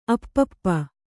♪ appappa